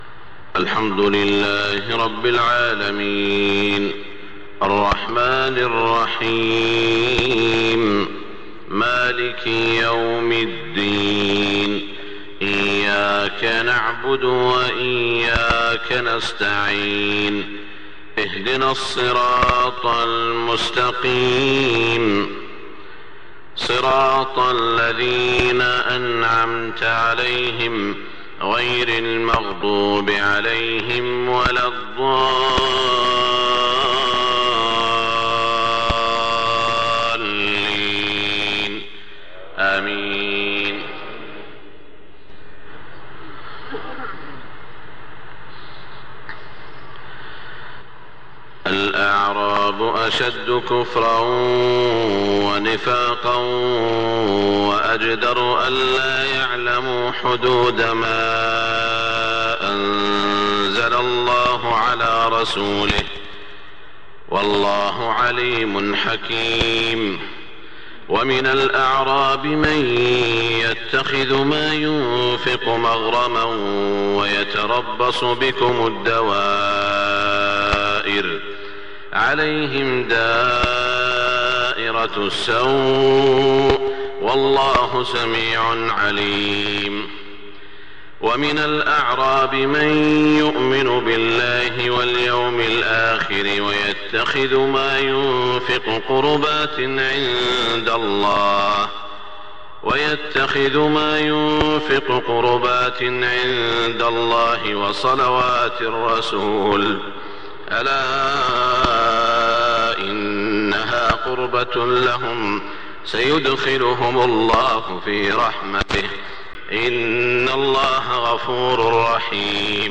صلاة الفجر 2-8-1427 من سورة التوبة > 1427 🕋 > الفروض - تلاوات الحرمين